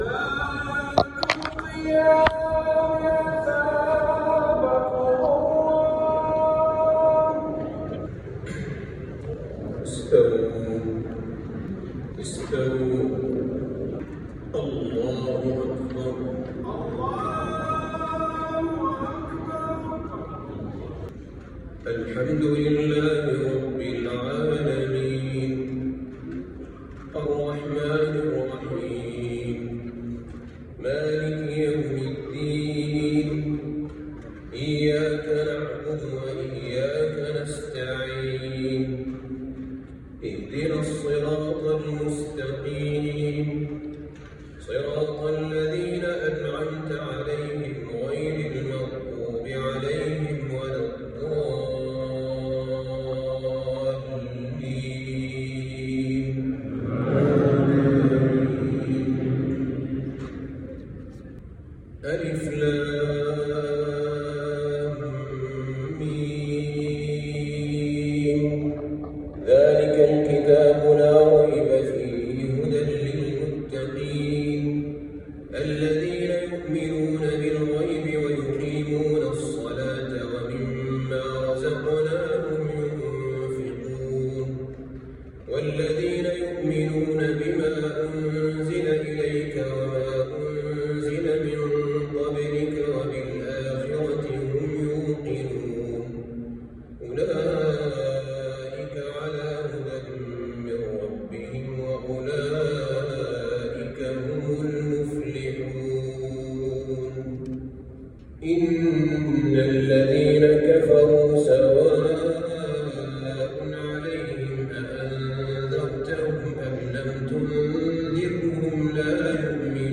تحبير بديع لفواتح سورة البقرة (١-٦٦) للشيخ أحمد طالب الجمعة ليلة ١ رمضان ١٤٤٣هـ (تسجيل معدل من المسجد)